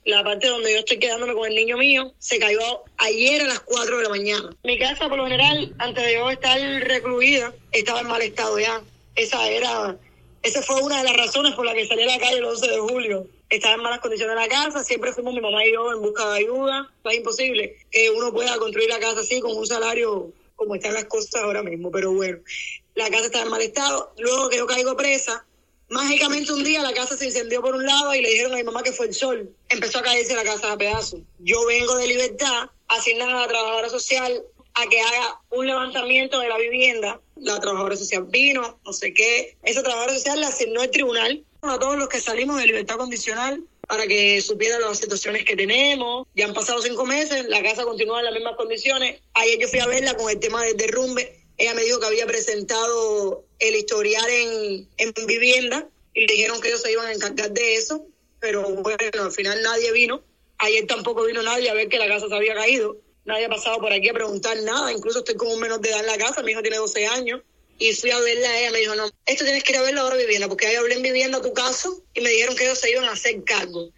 Audios de Historias